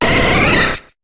Cri de Voltali dans Pokémon HOME.